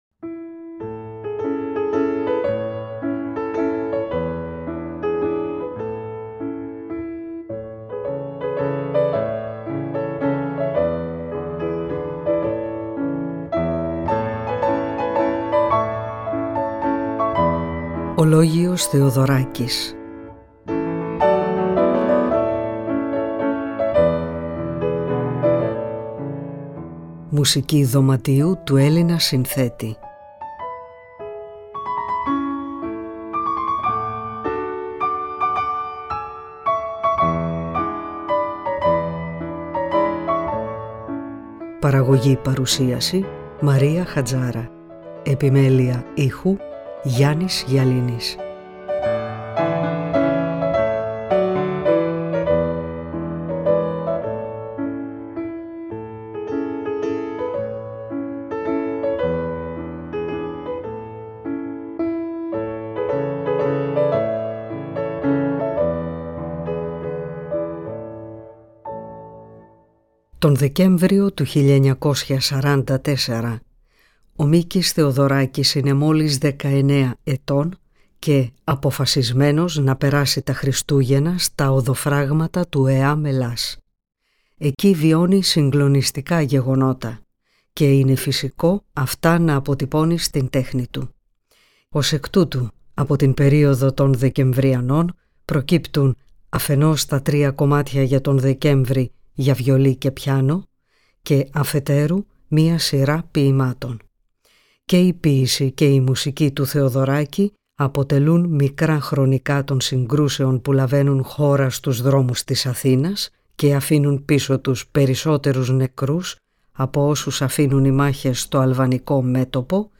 Μουσική Δωματίου του Μίκη Θεοδωράκη
Ανάγνωση ποίησης